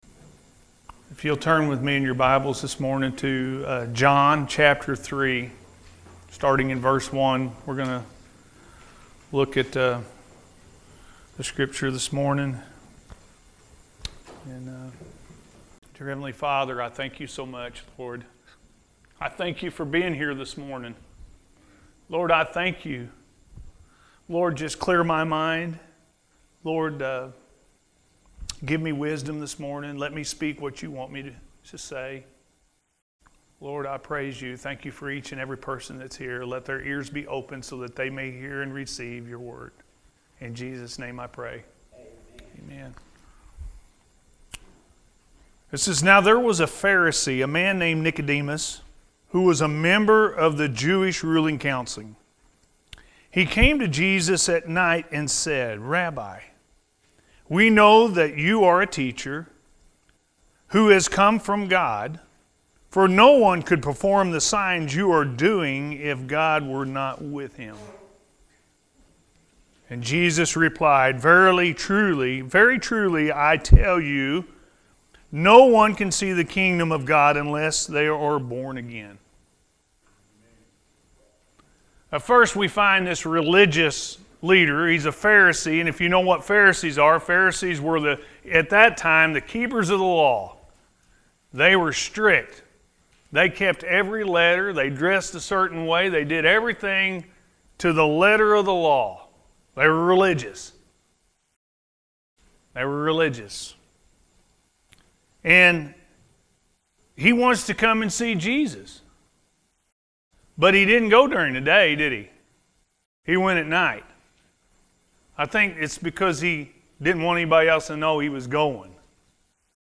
Are You Born Again – AM Service